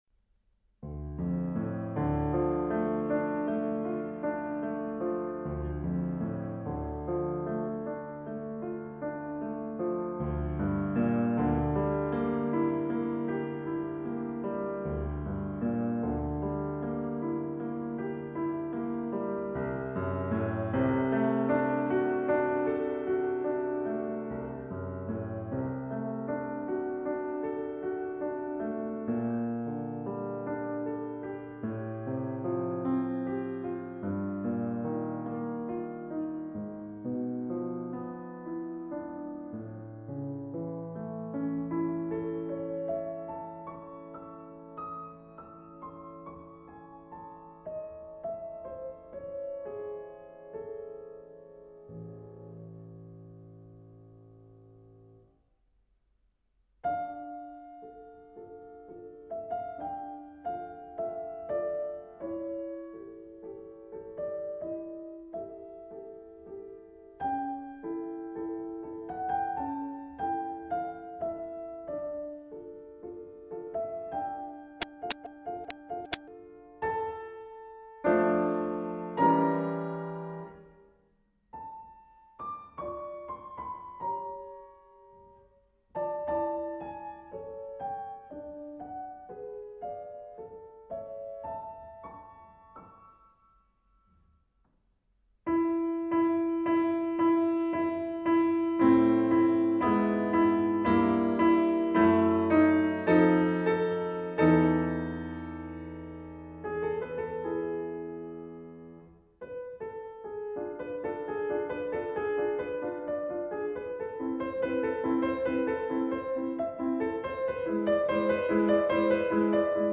BGM:D小调幻想曲
D小调幻想曲.mp3